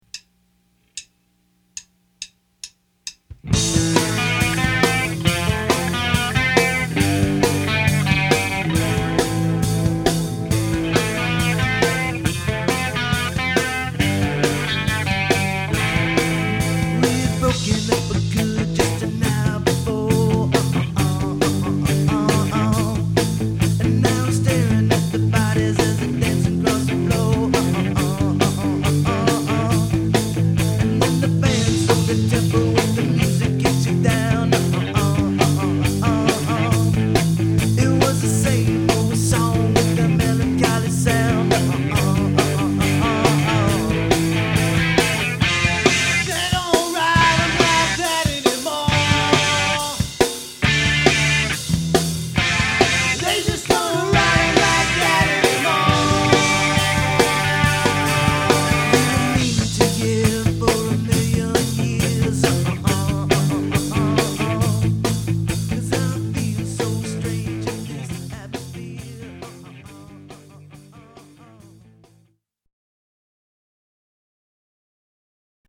Cover Songs